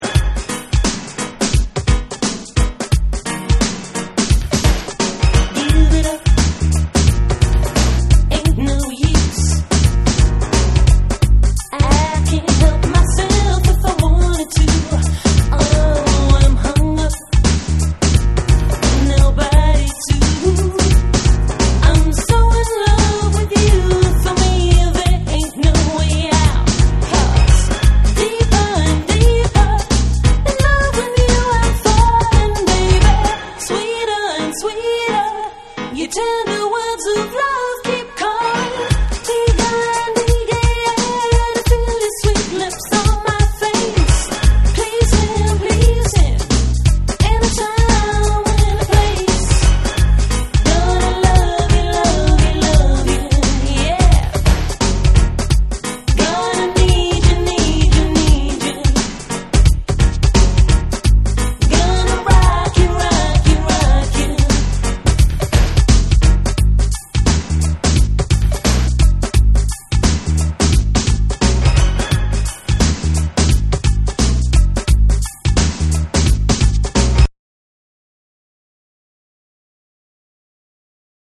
程よくレゲエの要素も含んだナイストラックです！
BREAKBEATS